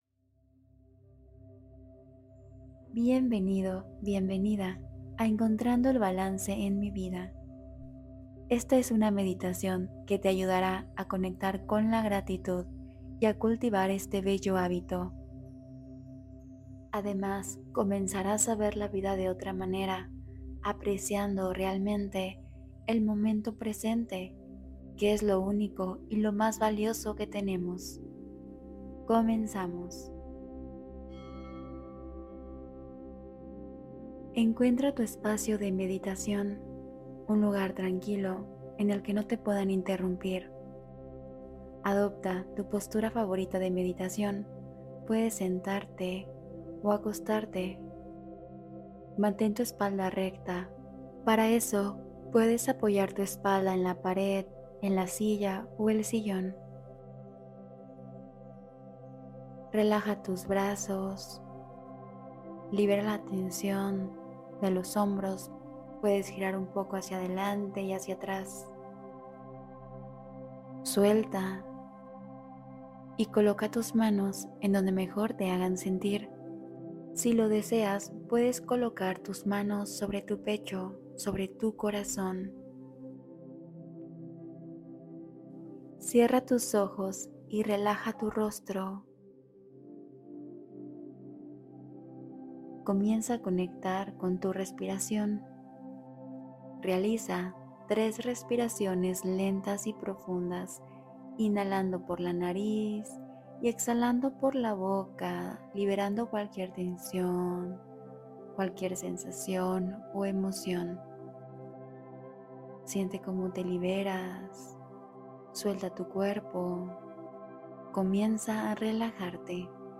Accede a Tu Versión Más Elevada: Meditación Guiada de Expansión Interior